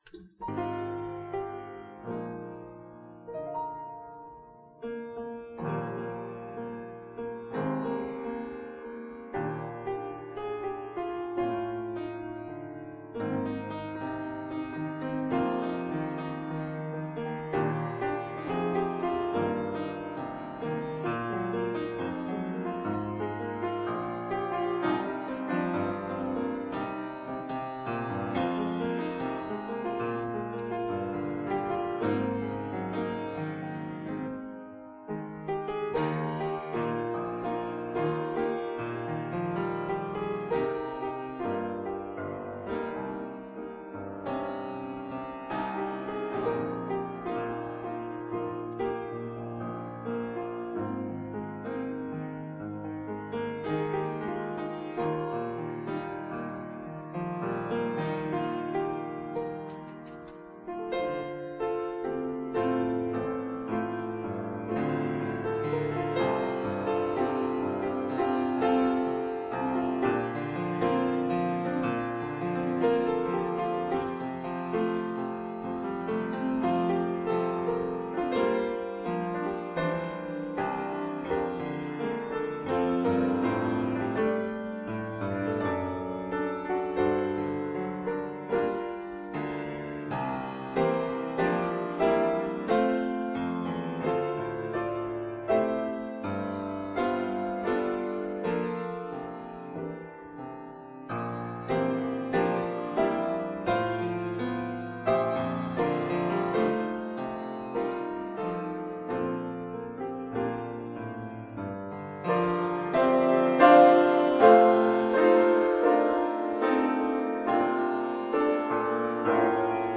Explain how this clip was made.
Fellowship Church is pleased to offer this live service at 10AM via Zoom.